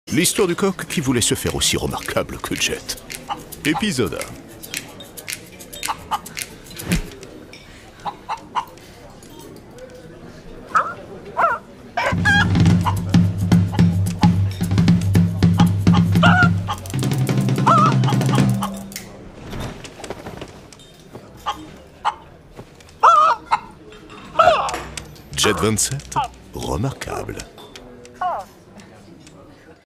Prestation voix-off décalée et snob pour la publicité GET Remarquable : humour et originalité
Voix snob et absurde.
Publicité web pour GET 27.
Avec une tonalité de voix snob et drôle, j’ai apporté une touche d’humour et d’absurde à la publicité GET Remarquable.
Pour la publicité GET Remarquable, j’ai usé de ma voix médium grave, de mon ton décalé et snob, pour faire ressortir un côté à la fois drôle et absurde, afin de marquer les esprits.